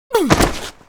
WAV · 77 KB · 單聲道 (1ch)